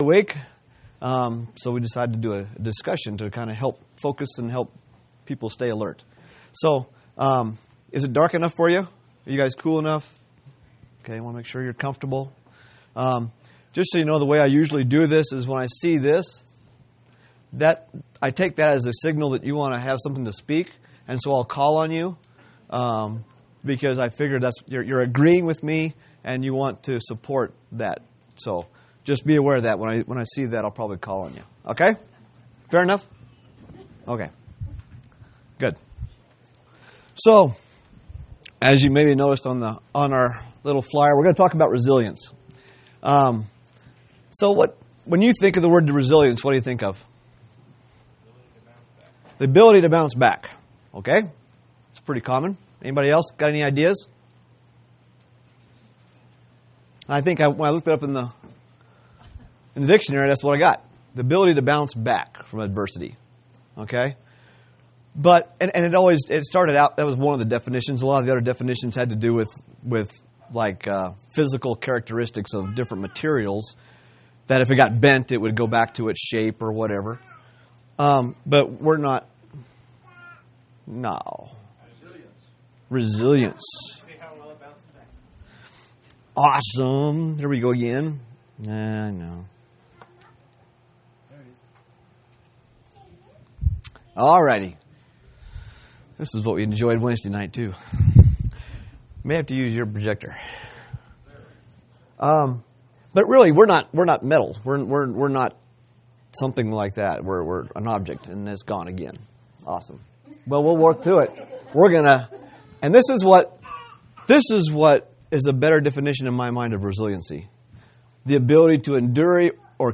6/9/2018 Location: Colorado Reunion Event